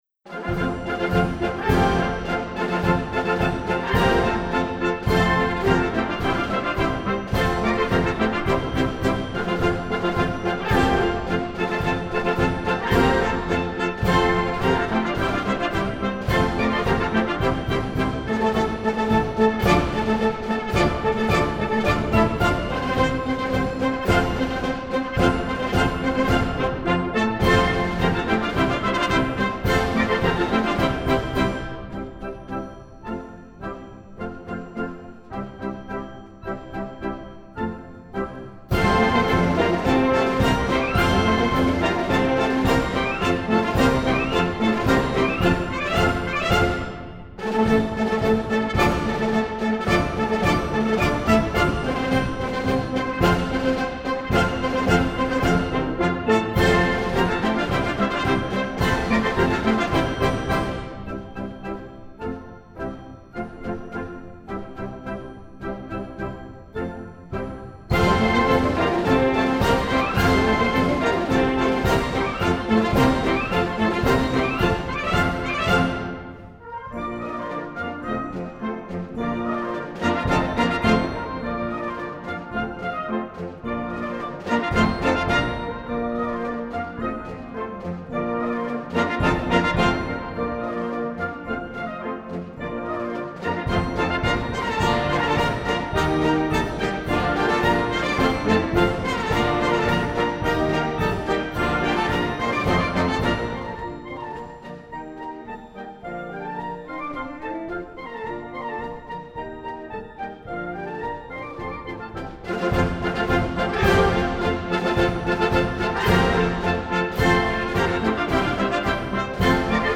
Ludwig van Beethoven’s Military March No. 1 in F, “York’sher,” is a tattoo march or Zapfenstreich (literally translated as “strike the tap”) that was an evening military call signaling to tavern owners to close the tap for the night.
The piece was later arranged for modern wind instruments by Johannes Schade.